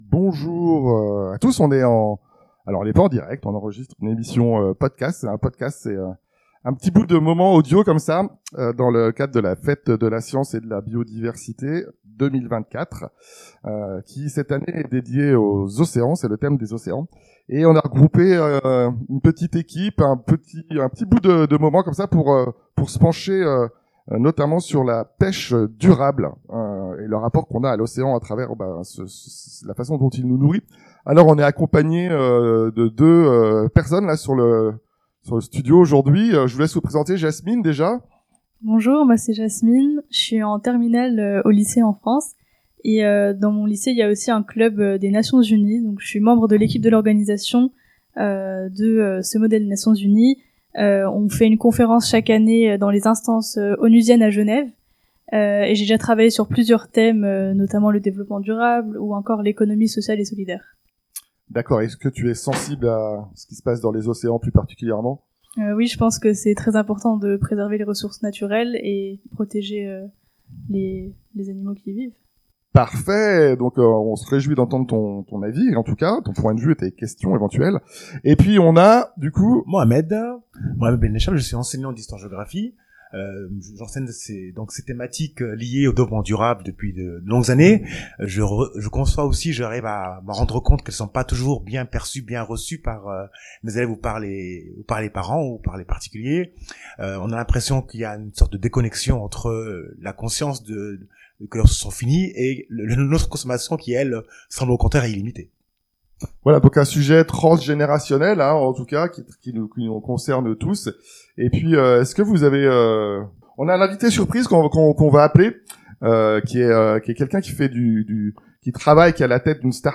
Dans le cadre de la fête de la science 2024, autour du thème Ocean de savoirs: un atelier podcast collaboratif autour des enjeux de la pêche durable en France.